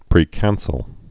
(prē-kănsəl)